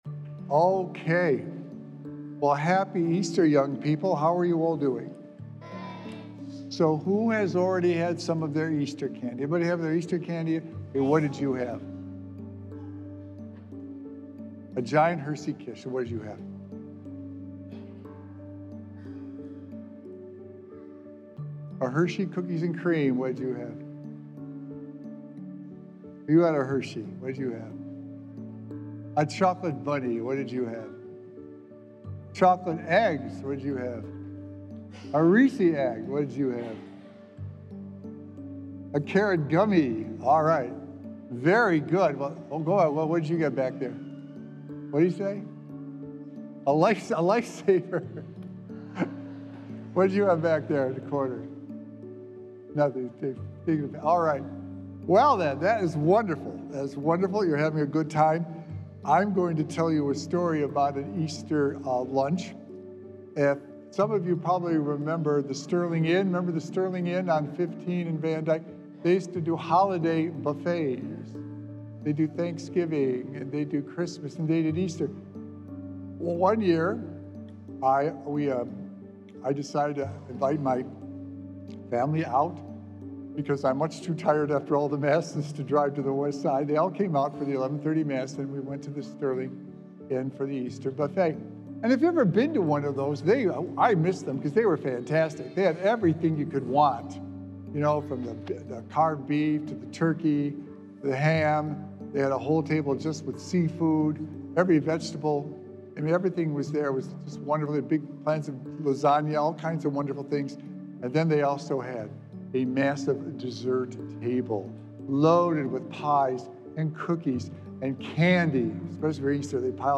Sacred Echoes - Weekly Homilies Revealed
In order to live a well-balanced, healthy life, we need to make sure we are consuming Christ. Recorded Live on Sunday, April 20th, 2025 at St. Malachy Catholic Church